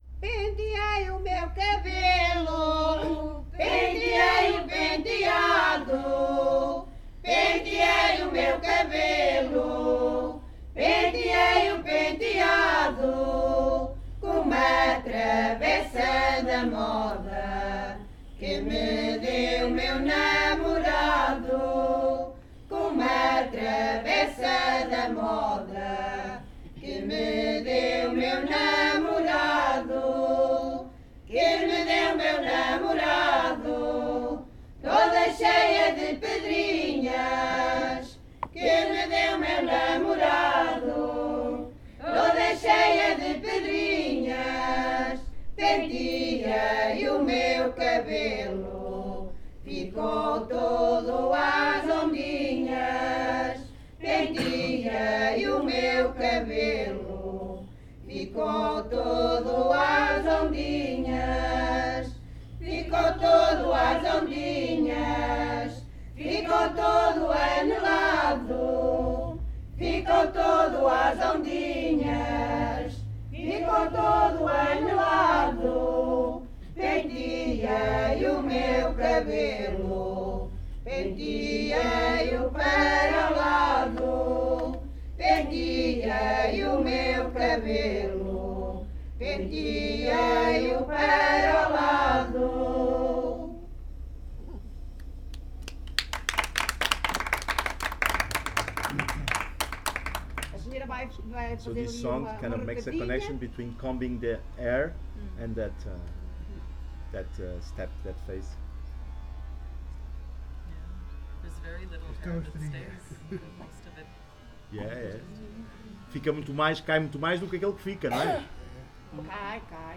Grupo Etnográfico de Trajes e Cantares do Linho de Várzea de Calde durante o encontro SoCCos em Portugal - Penteei o meu cabelo.
NODAR.00680 – Grupo de Trajes e Cantares de Várzea de Calde durante o encontro SoCCos em Portugal – Penteai o meu cabelo (Várzea de Calde, Viseu)